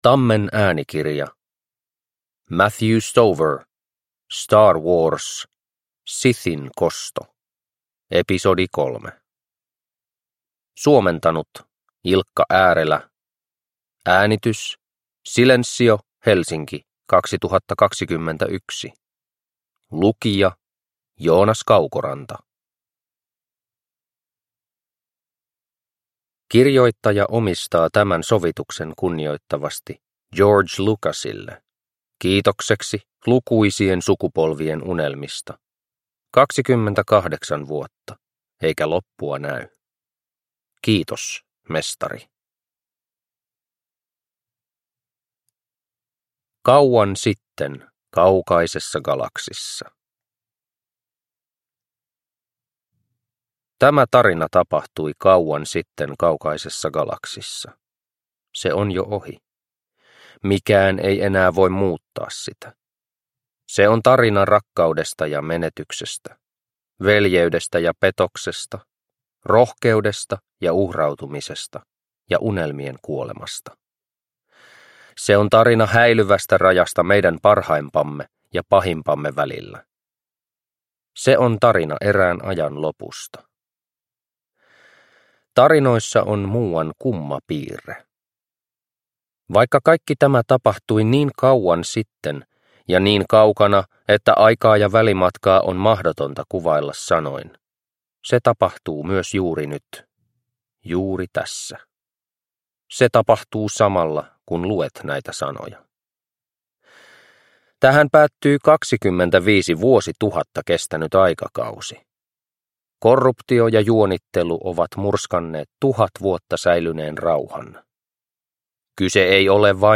Star Wars. Sithin kosto – Ljudbok – Laddas ner